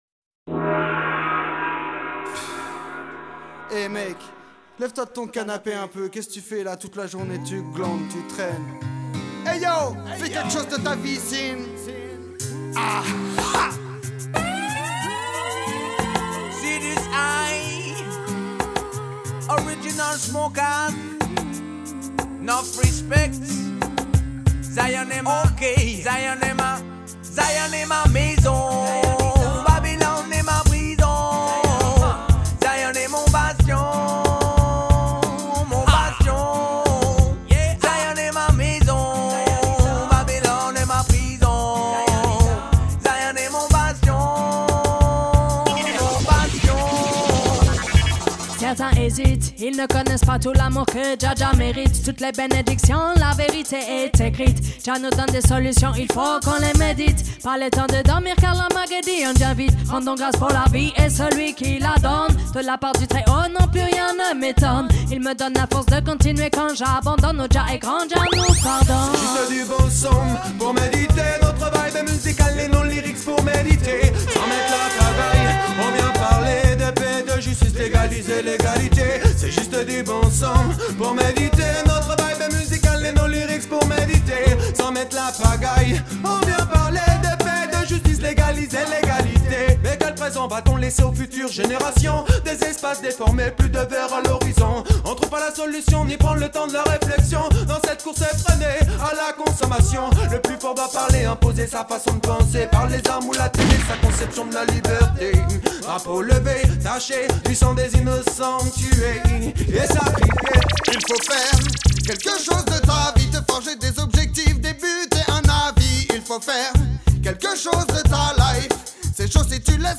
Le mix promo